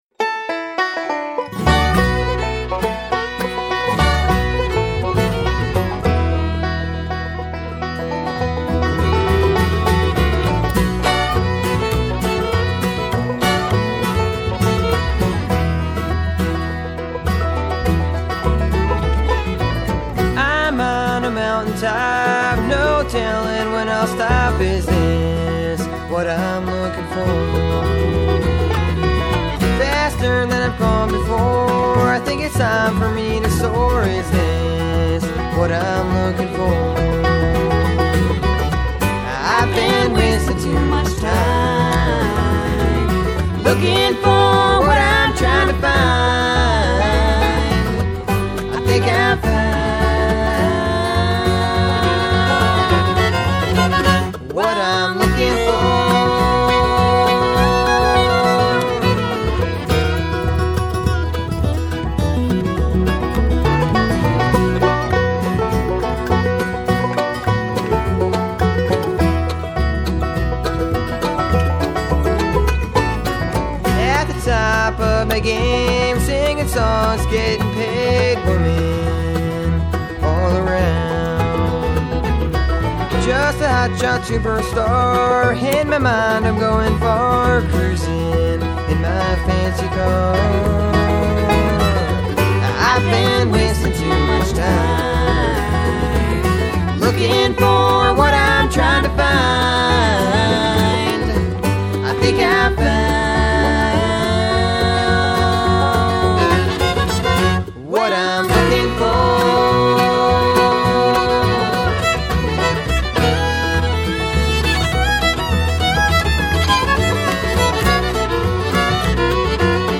banjo
fiddle & lead vocal
mandolin & harmony vocal
bass
guitar
harmony vocals